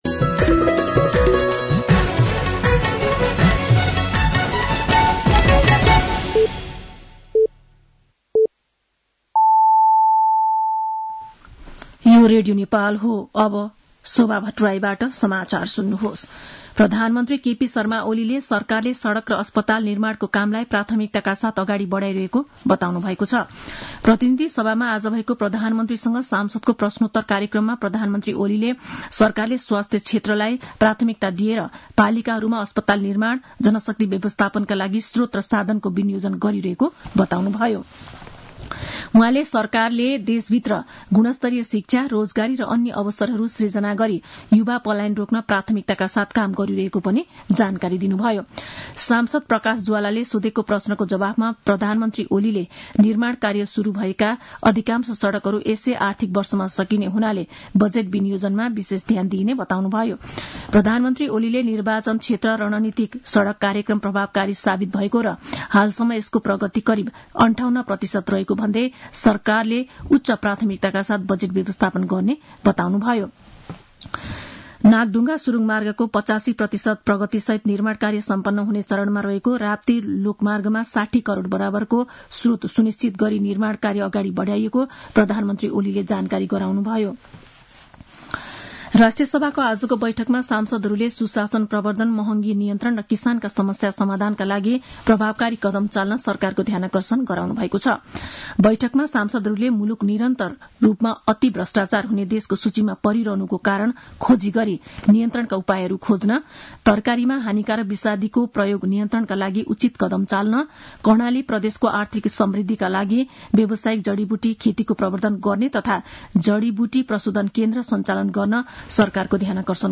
साँझ ५ बजेको नेपाली समाचार : ५ फागुन , २०८१
5-pm-news-2.mp3